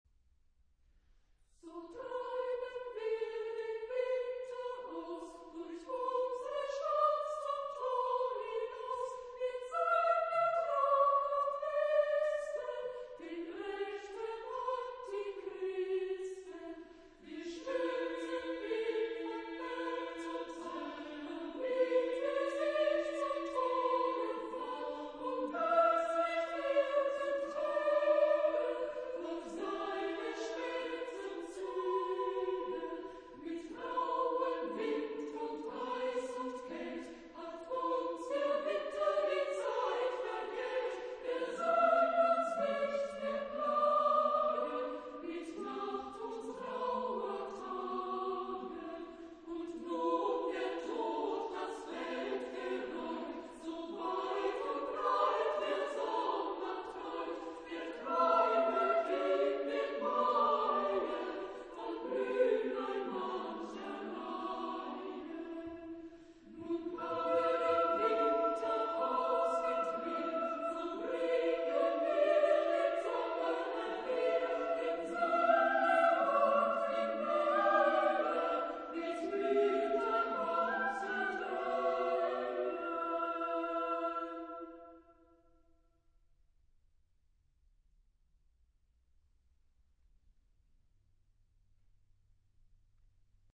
Genre-Style-Forme : Profane ; Populaire
Type de choeur : SSAA  (4 voix égales de femmes )
Réf. discographique : Internationaler Kammerchor Wettbewerb Marktoberdorf